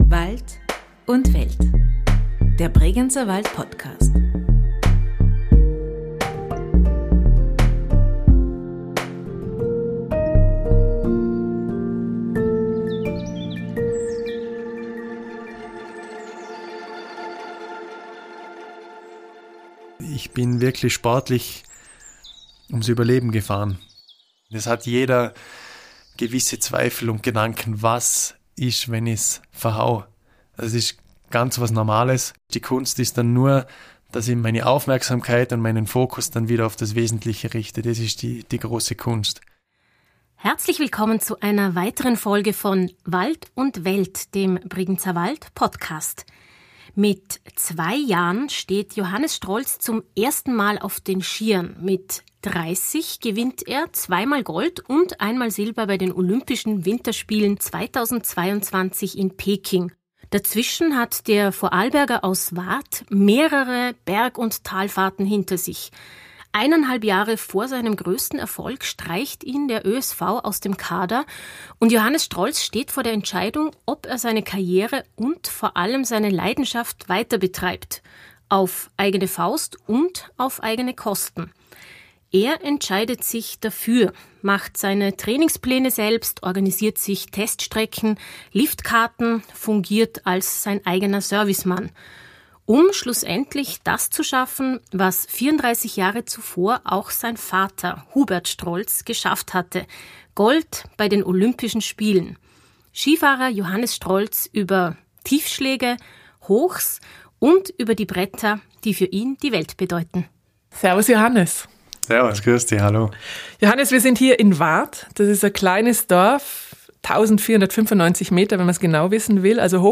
in seinem Elternhaus in Warth